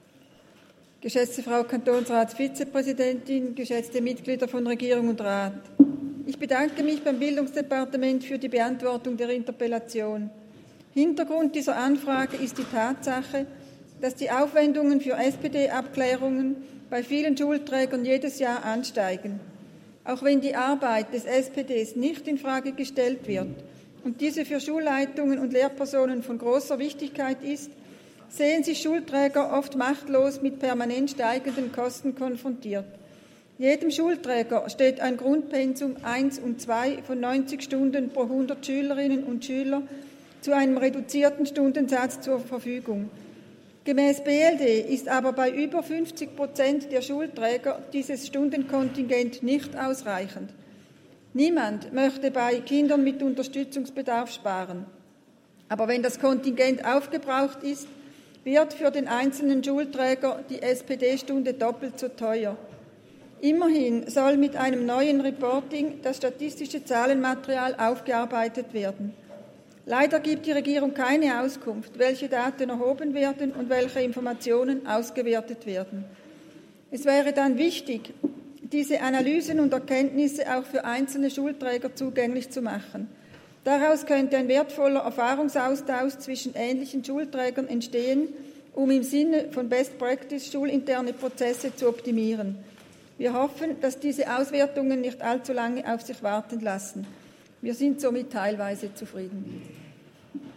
15.2.2023Wortmeldung
Session des Kantonsrates vom 13. bis 15. Februar 2023, Frühjahrssession